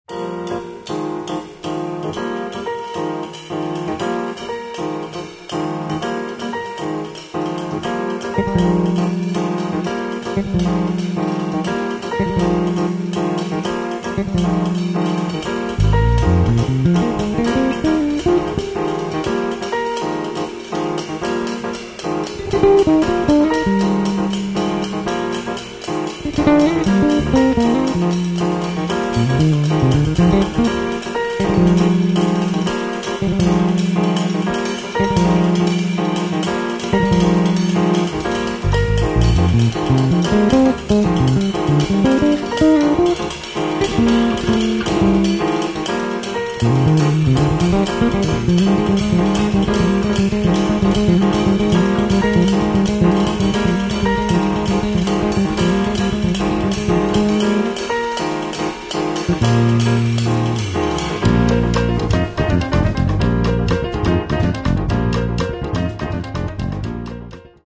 piano
bass
drums